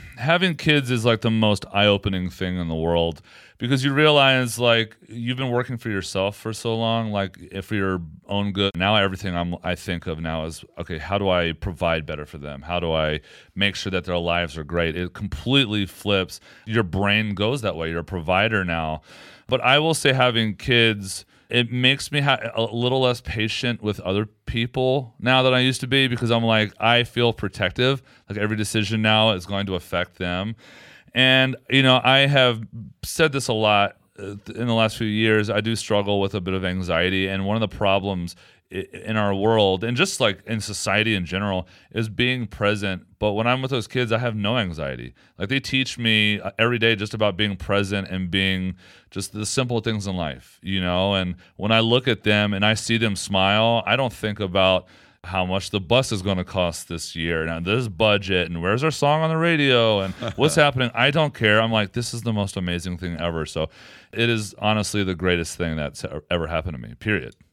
Brothers Osborne's John Osborne talks about his two children keep him grounded.